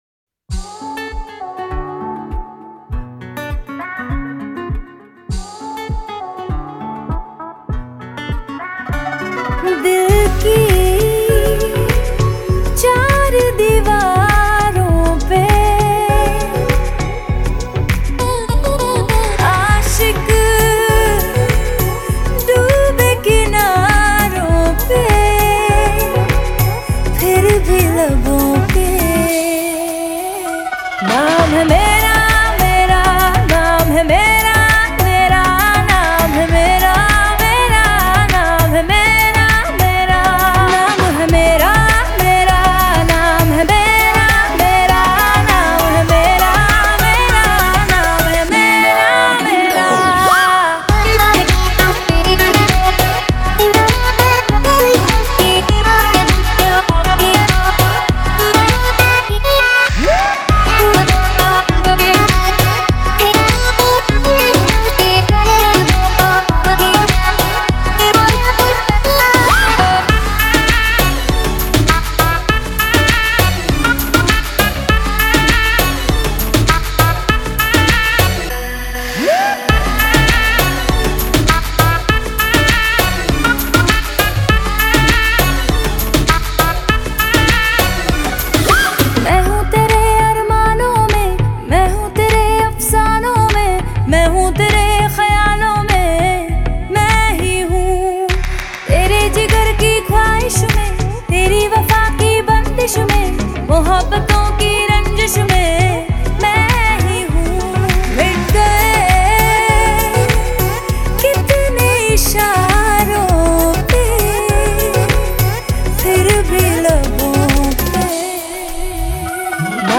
это яркая и энергичная песня в жанре поп